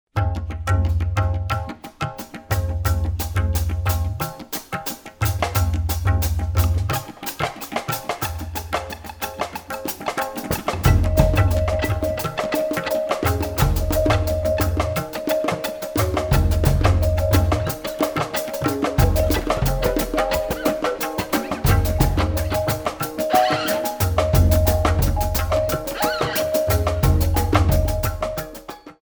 harmonized tabla, djembe,
djun djun, marimba, drumset
electric guitar